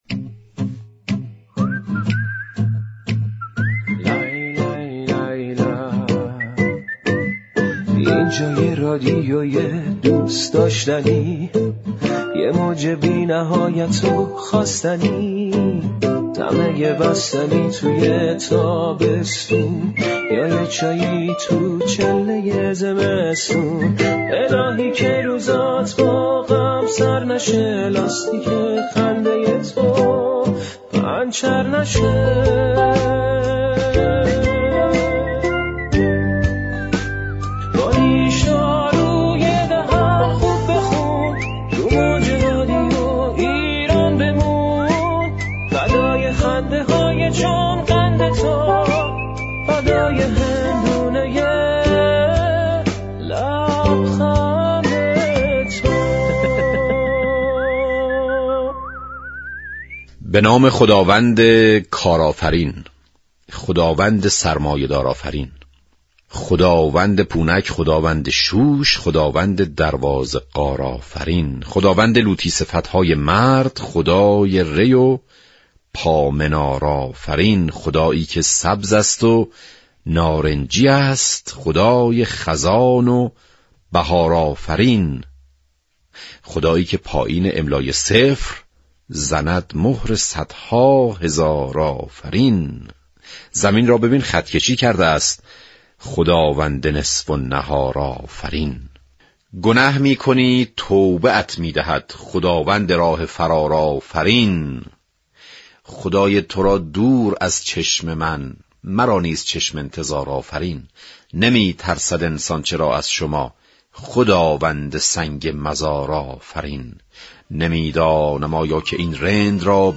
برنامه «نیشدارو» رادیو ایران داستان ها و نوشته های طنز را برای شنوندگان بازگو می كند